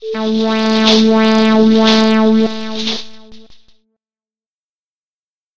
描述：在基础上，手与脸之间摩擦。 使用ZoomH6录制，作为立体声的48kHz 24bit WAV文件。
标签： 重击 上午 湿 擦拭 另一方面 变化 化妆 基金会 基金会 刷牙 FASE 湿度 常规 各种 滑腻 手指轻扫 OWI 化妆品 面霜 触摸 皮肤
声道立体声